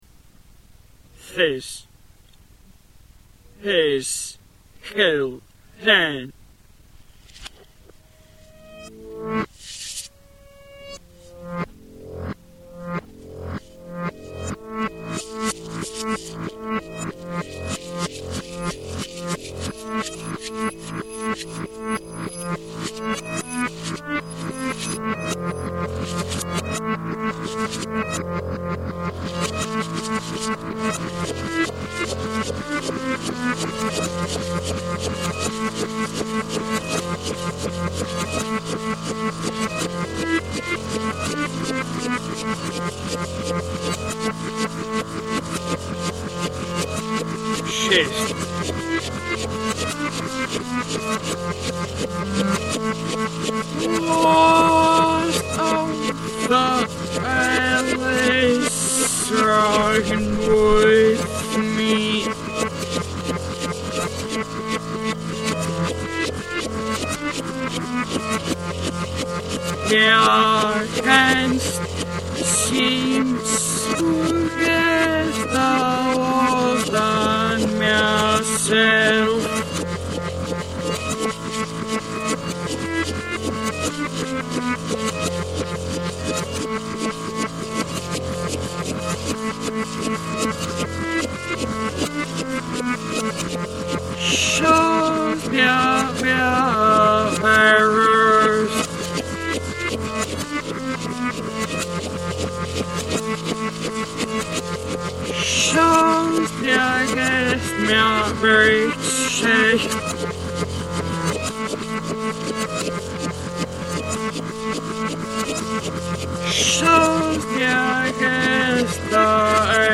lyrics sound (somewhat) forwards again.